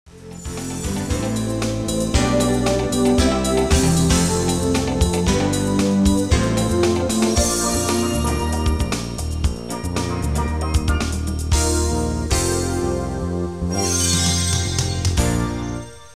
2. 背景音乐